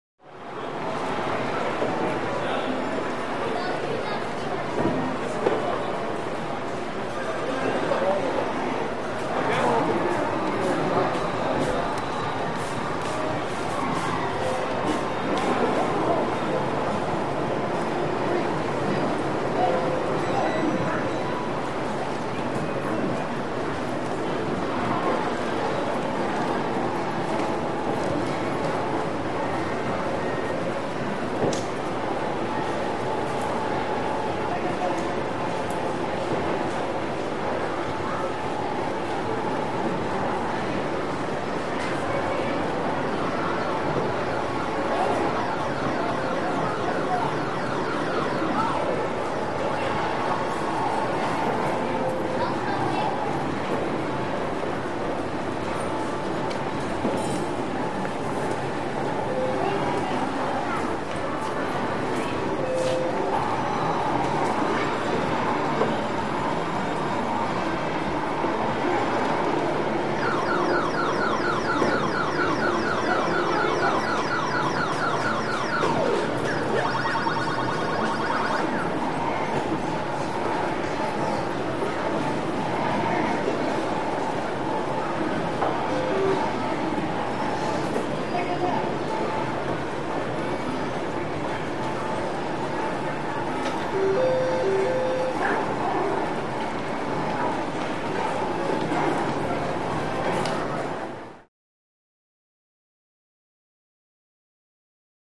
Video Arcade Room Ambience; Wide Perspective Electronic Game Sounds With Reverberated Walla And Footsteps.